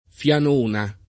Fianona [ f L an 1 na ] top. (Istria)